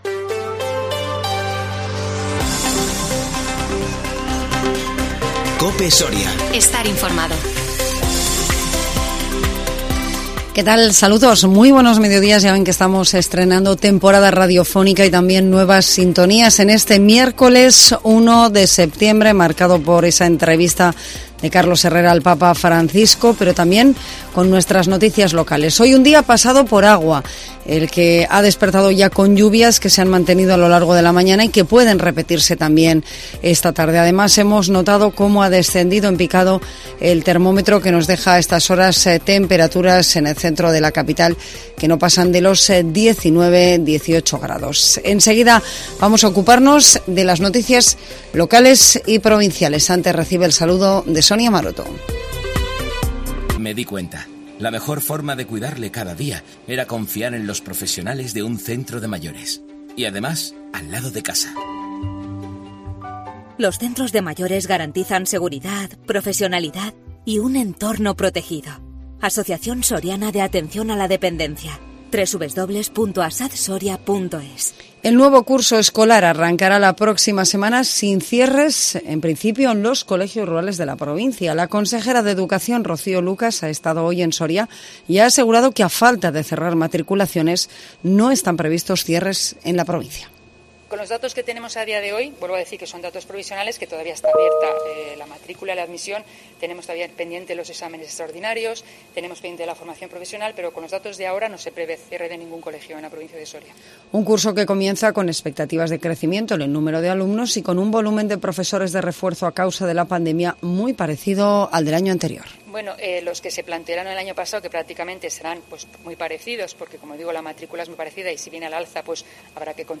INFORMATIVO MEDIODÍA 1 SEPTIEMBRE 2021